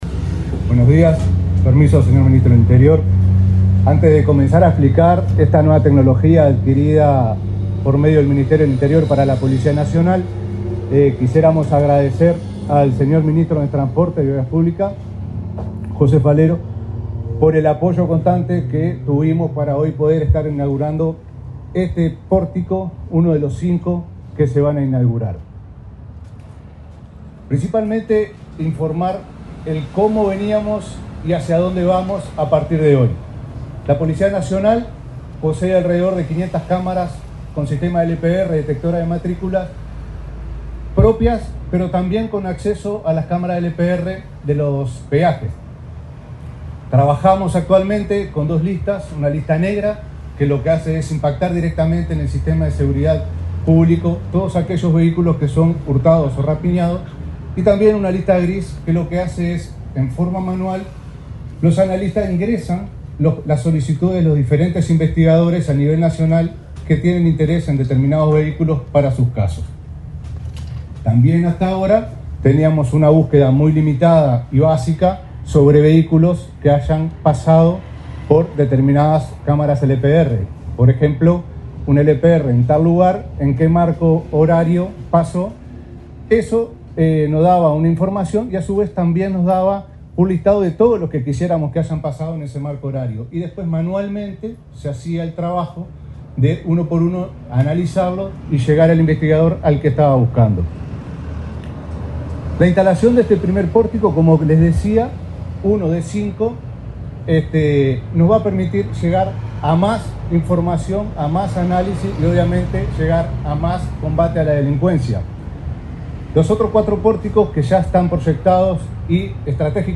El director del Centro de Comando Unificado, Gabriel Lima, se expresó en el acto, realizado este viernes 25 en Montevideo.